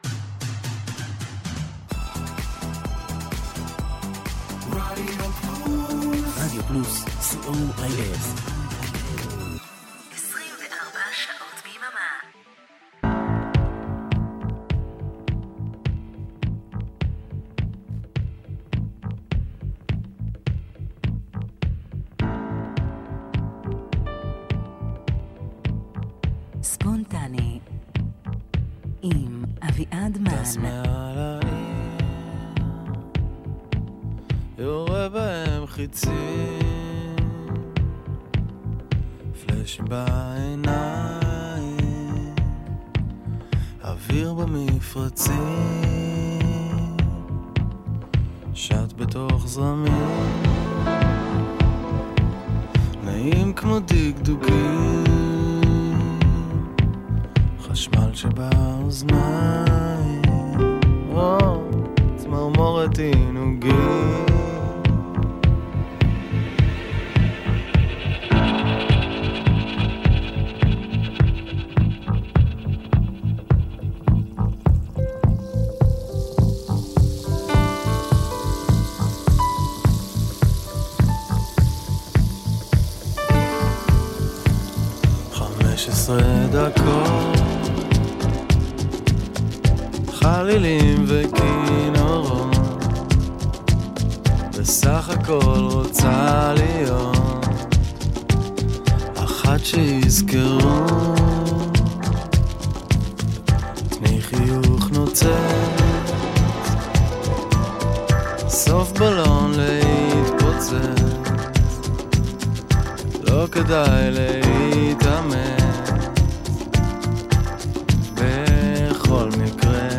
שעה של שירים רגועים ויפים לכבוד שבת, תהנו 🙂